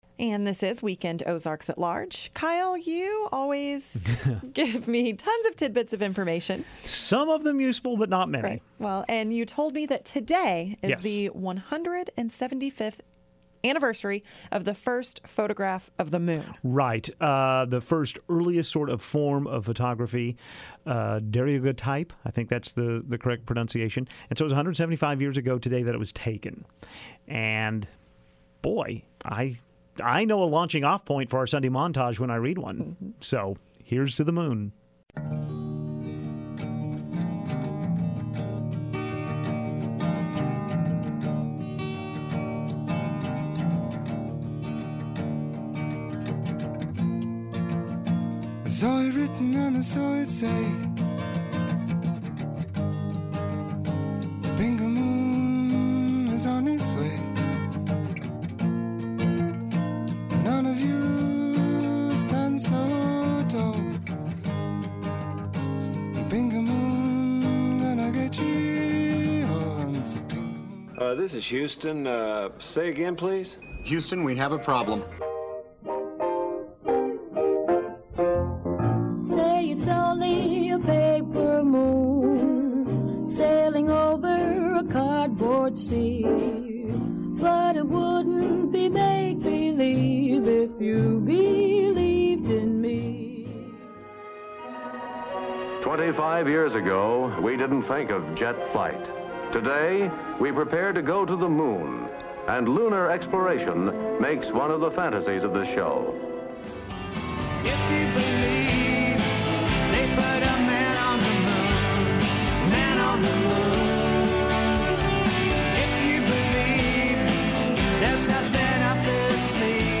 To celebrate the 175th anniversary of the first photograph of the moon, here are the clips we included in our montage devoted to earth's favorite satellite: 1.